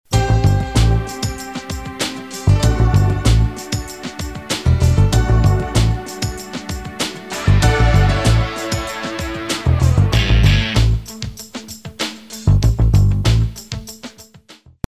Contemporary Music Samples
Contemporary 51a